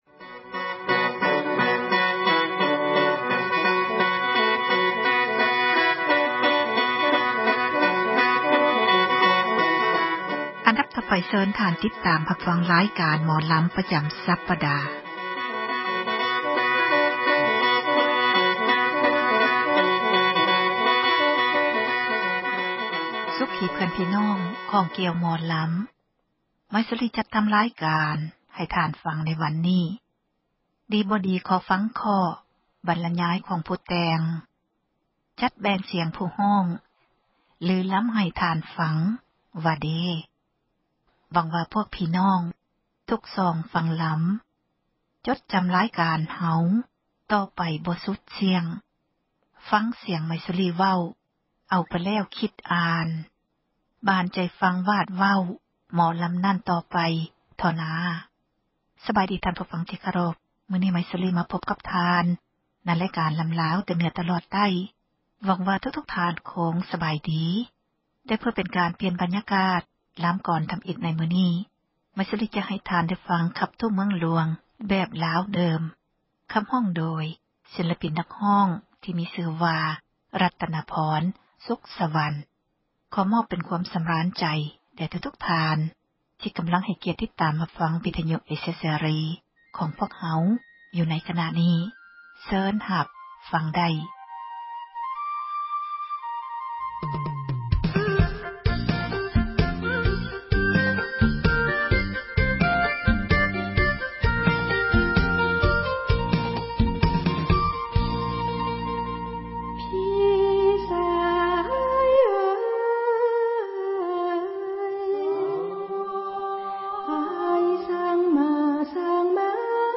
ສິລປະ ການຂັບລໍາ ຂອງ ເຊື້ອສາຍ ລາວ ໃນແຕ່ລະ ຊົນເຜົ່າ ແຕ່ລະ ພື້ນເມືອງ.